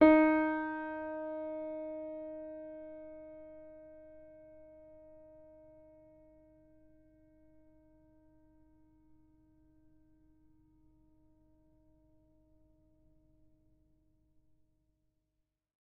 sampler example using salamander grand piano
Ds4.ogg